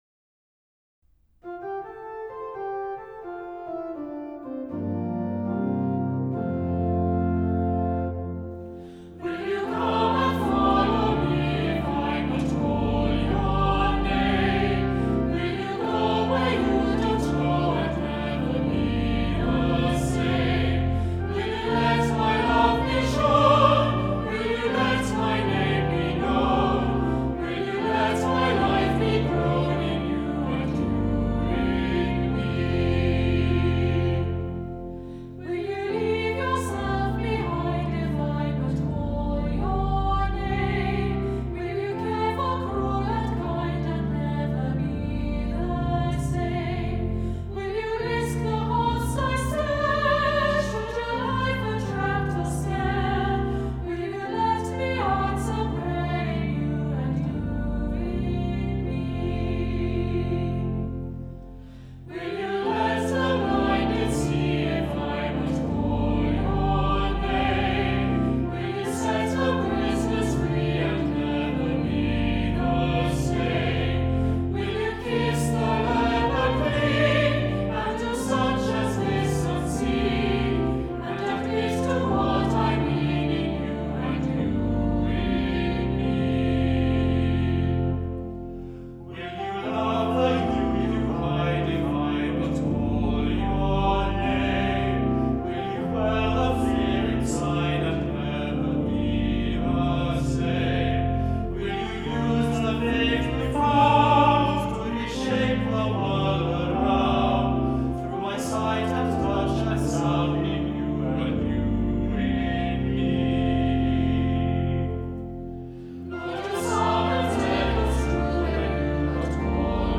Hymns and songs were recorded remotely by the Choral Scholars of St Martin-in-the-Fields in their homes, and edited together.
Offertory hymn, Will you come and follow me